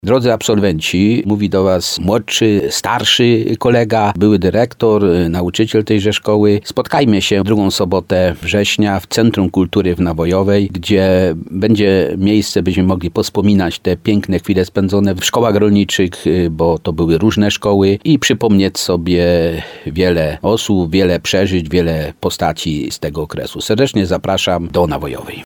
– mówi wójt gminy Nawojowa Stanisław Kiełbasa.